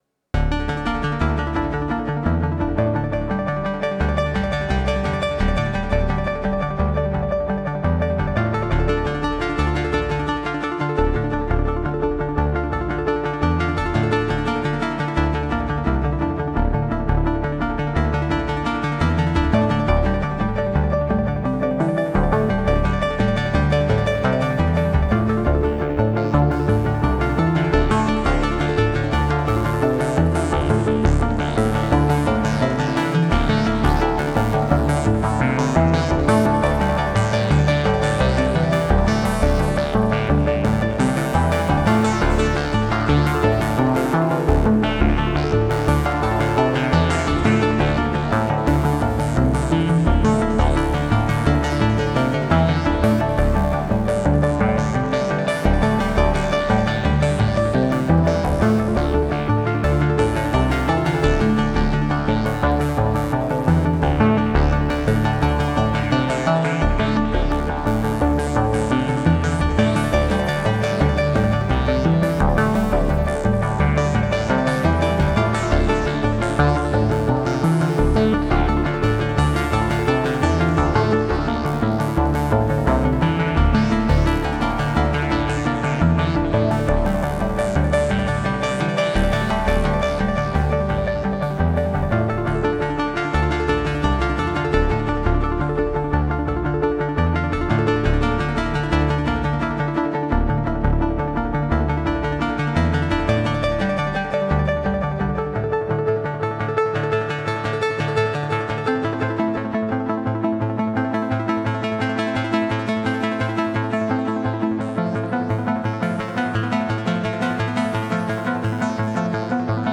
analog synthesizer sequencer roots